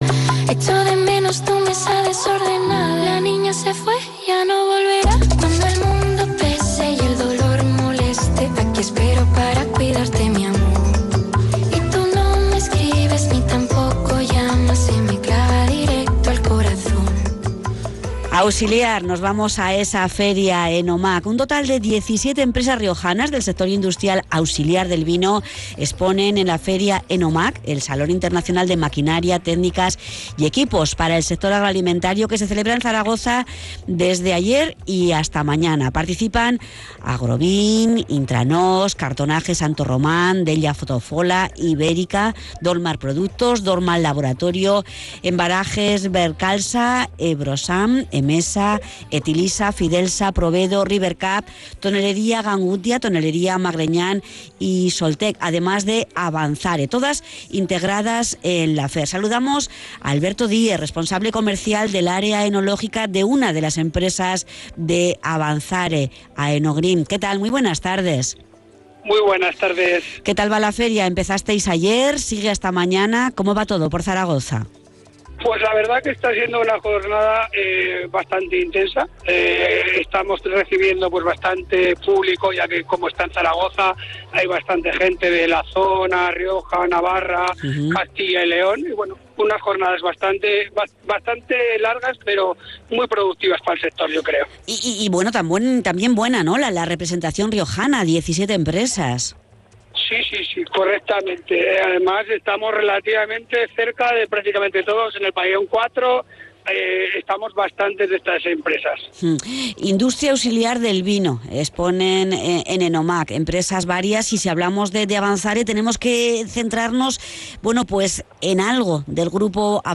ENTREVISTA_ONDA_CERO_LA_RIOJA_.mp3